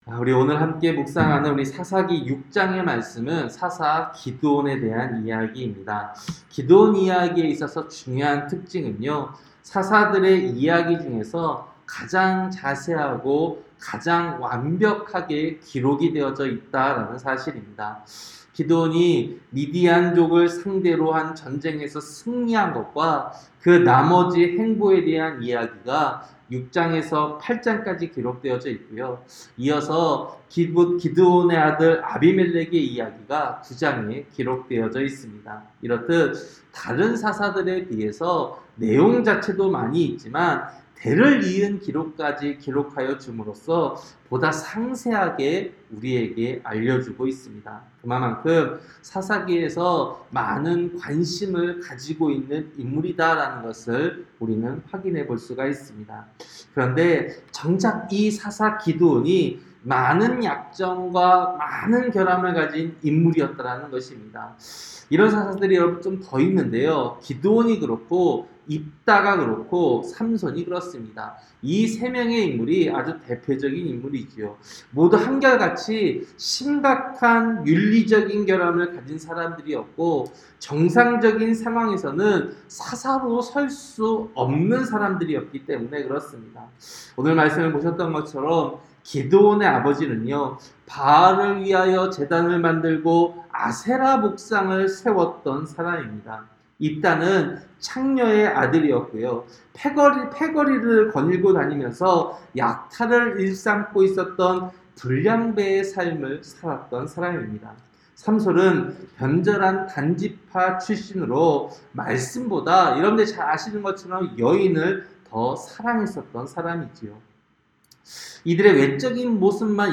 새벽설교-사사기 6장